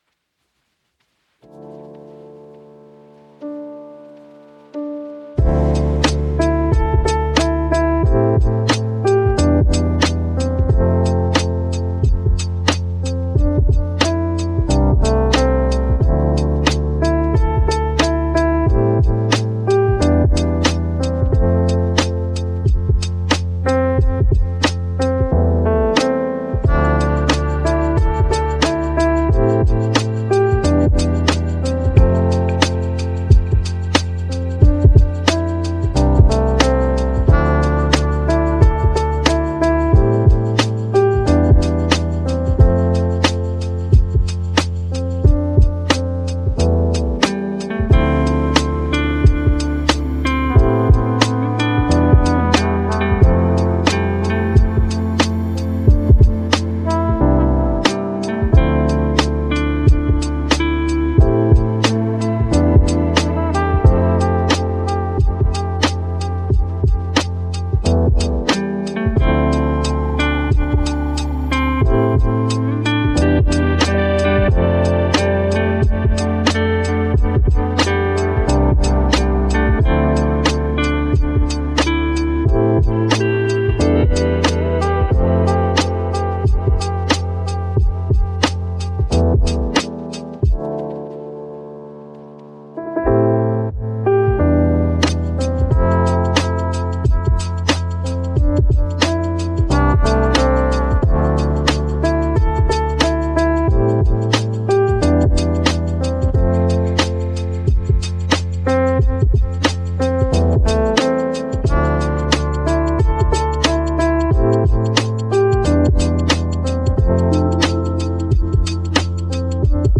Free Chill Trip-Hop & Jazzy Hip Hop (No Copyright)